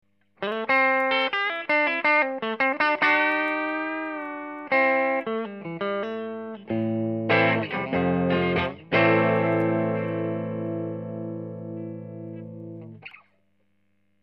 meme gratte type télé, meme ampli B4 (EF184+6V6 bias 70%) sans correction de tonalité, meme position du potard de volume de l'ampli, meme micro, a la meme position/distance... il n'y a pas de normalisation des samples, juste une conversion en MP3 codec LAME qui compresse un peu j'en suis désolé.
sample 1 - transfo ultra-bas de gamme PP 10W 4k-8r type "public adress" utilisé en SE, primaire 2.6H
Personnellement, les 1 et 2 , je les trouve très "noisy", handicap pour le son clean.